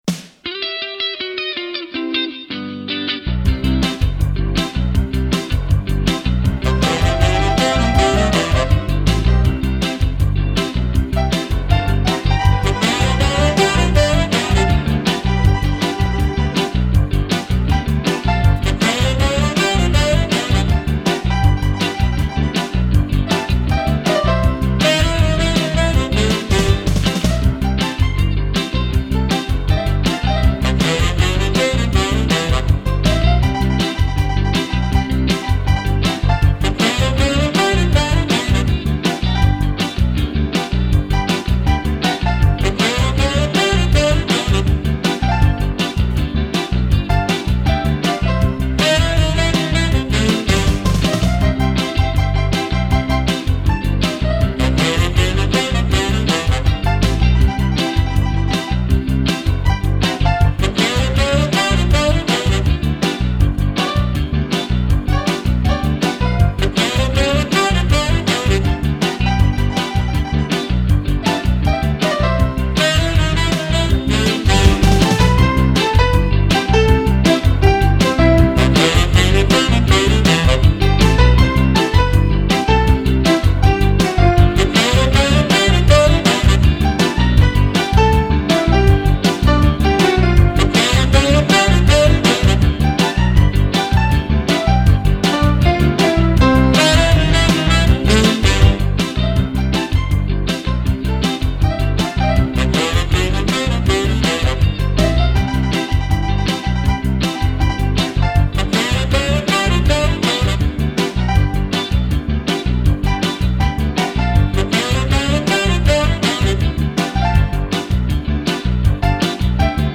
La piste musicale